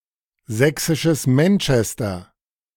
By the early 19th century, Chemnitz had become an industrial centre (sometimes called "the Saxon Manchester", German: Sächsisches Manchester, pronounced [ˈzɛksɪʃəs ˈmɛntʃɛstɐ]